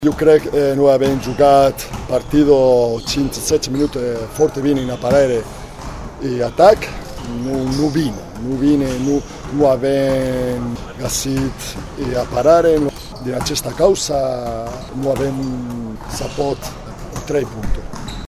Ascultaţi-i mai jos pe cei doi tehnicieni.